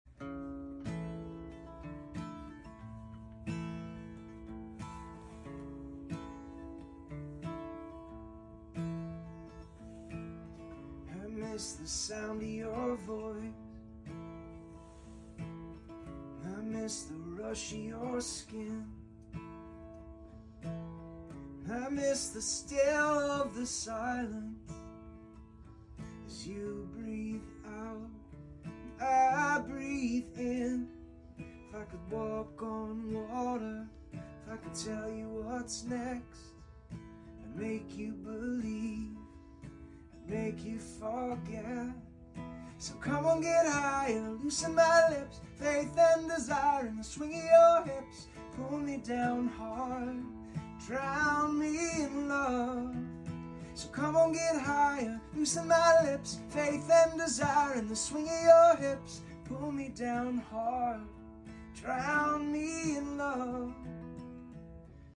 late-2000s banger